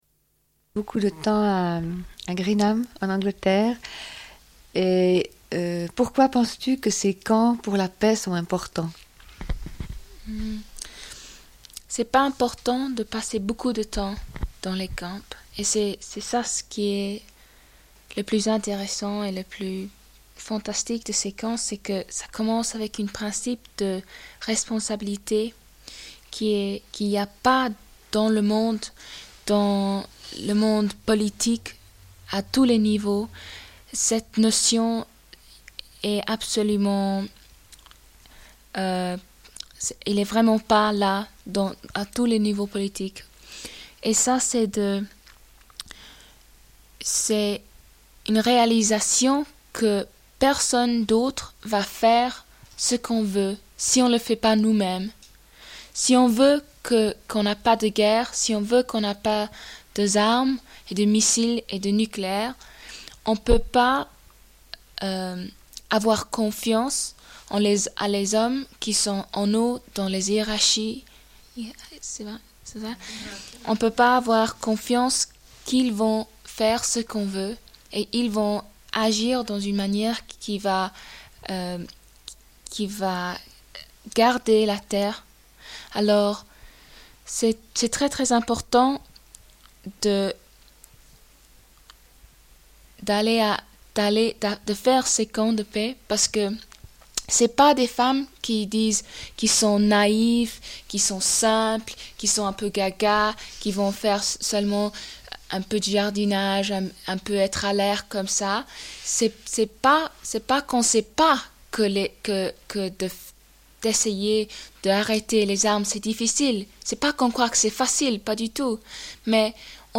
Rush de l'émission du 6 mars 1984. Discussion avec des femmes de Greenham Common, présentes à Genève à l'occasion de l'arrivée des marcheur·euse·s de la paix arrivants de Berlin.